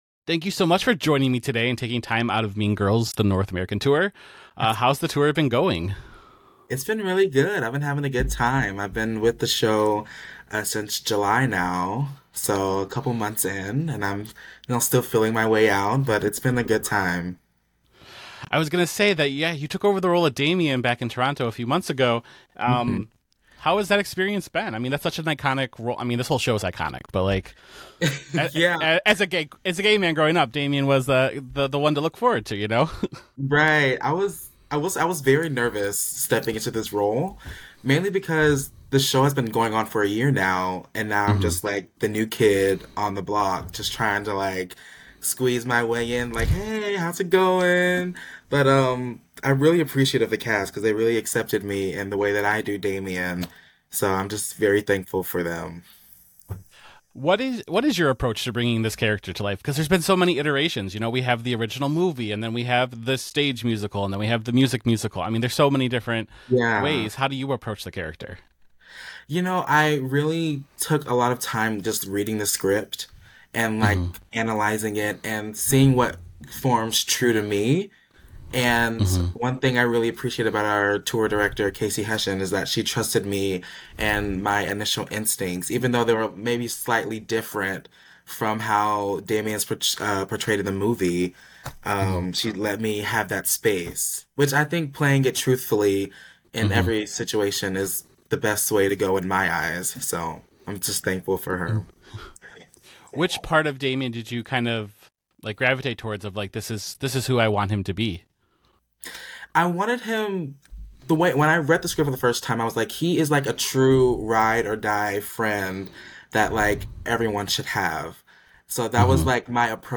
Exclusive Interview 16:07